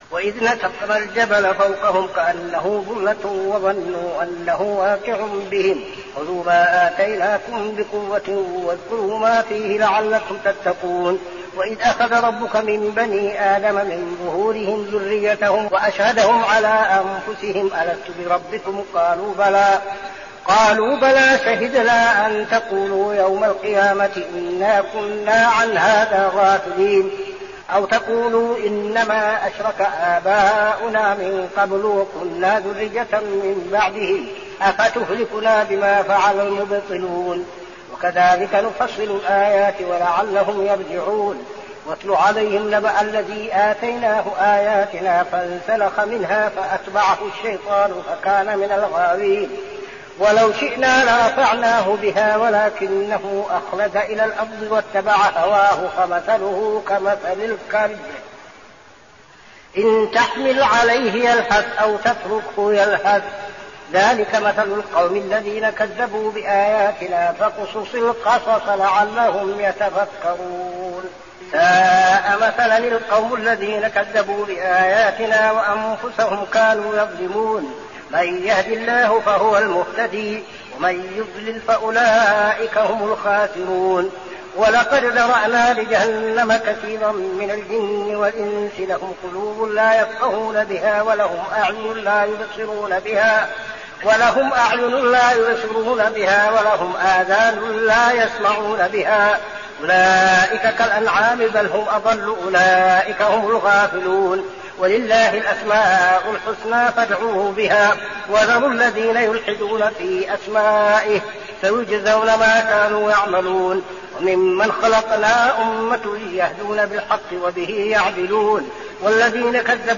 صلاة التراويح ليلة 8-9-1402هـ سورتي الأعراف 171-206 و الأنفال 1-40 | Tarawih Prayer Surah Al-A'raf and Al-Anfal > تراويح الحرم النبوي عام 1402 🕌 > التراويح - تلاوات الحرمين